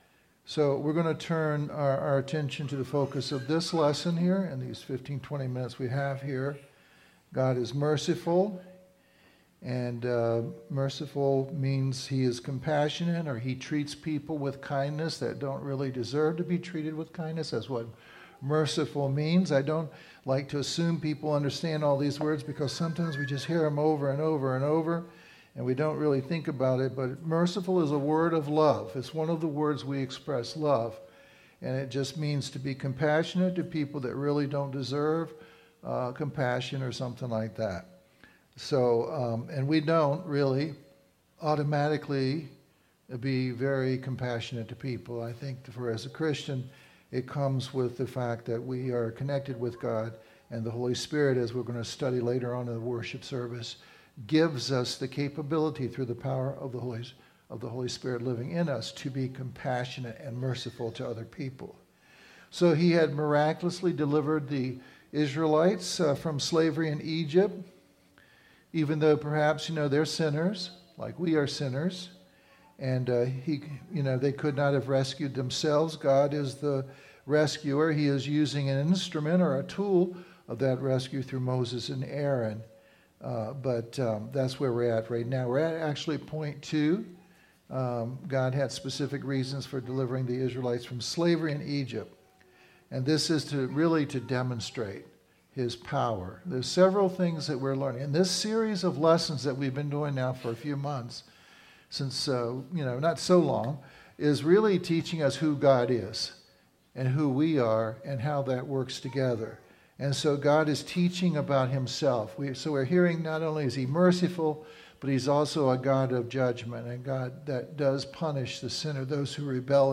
Service Type: Firm Foundations